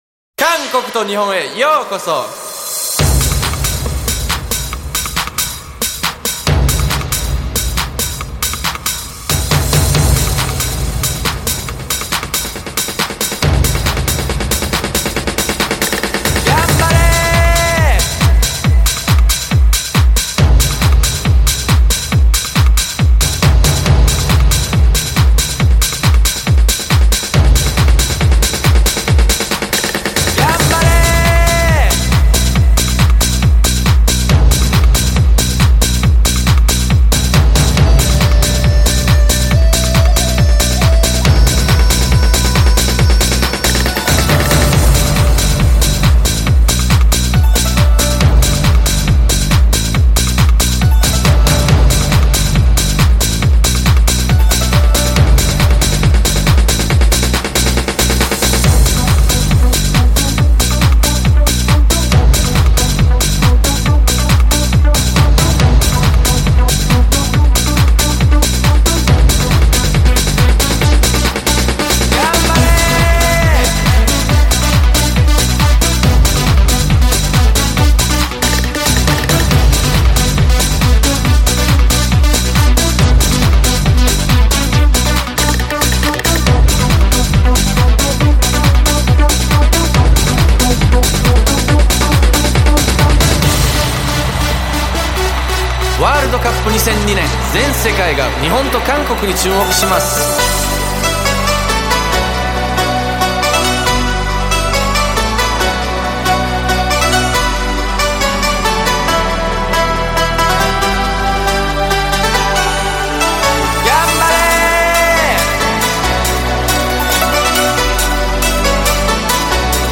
Жанр: Dance/Electronic